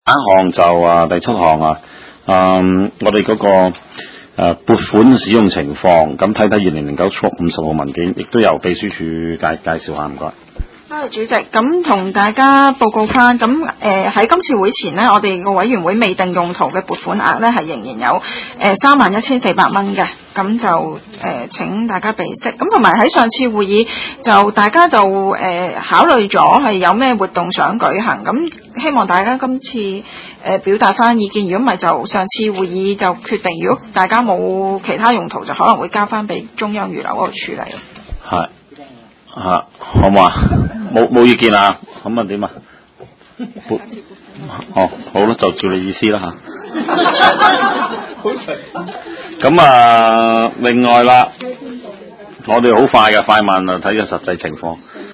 地區工程及設施管理委員會第十二次會議
灣仔民政事務處區議會會議室